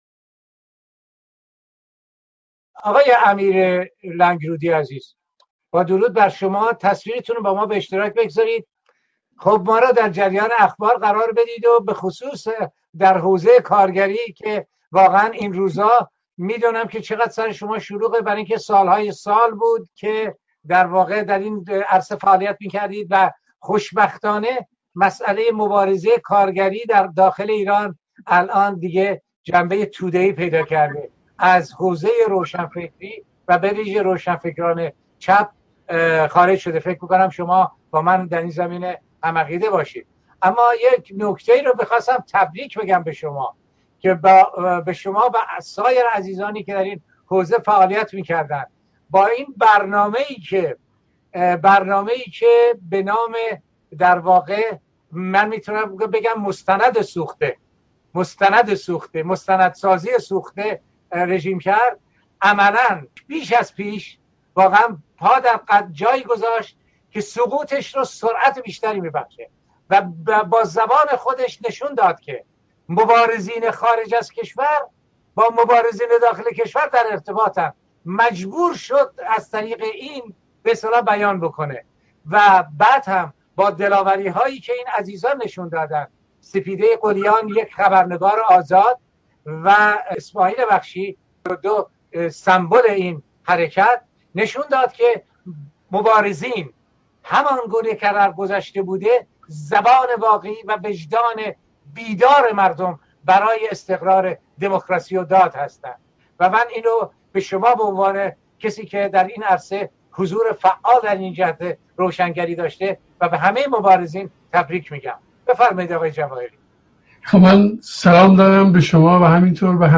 به گزارش گذار (سامانه دموکراسی و داد)، وبینار سراسری گذار از استبداد اسلامی به دموکراسی، با موضوع راهکارهای میدانی برای گذار خشونت پرهیز از استبداد اسلامی به دموکراسی، در جهت حمایت و شرکت فعال ایرانیان خارج از کشور از جنبش اعتراضی برای تغییرات بنیادی در حاکمیت سیاسی، با حضور جمعی از کنشگران مدنی، صاحب‌نظران و فعالان سیاسی، روز یک‌شنبه ۲۷ ژانویه ۲۰۱۹ (۷ بهمن‌ماه ۱۳۹۷) برگزار شد.